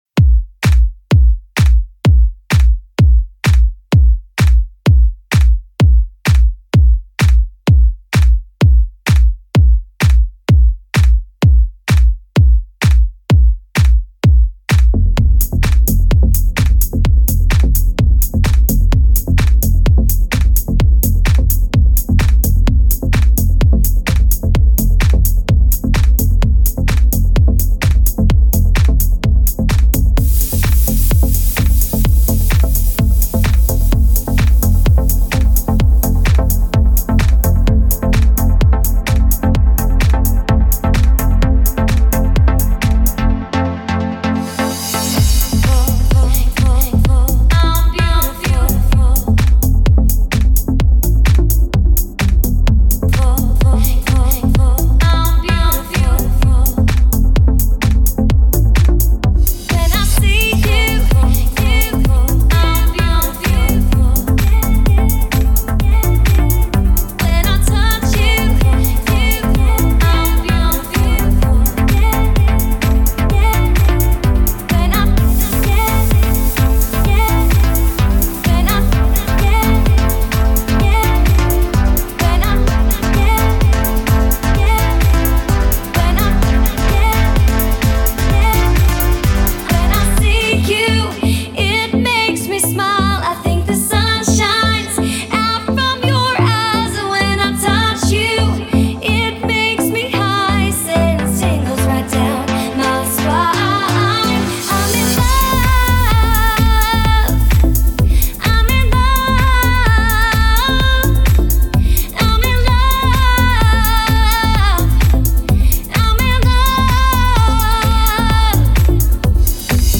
Mixed for broadcast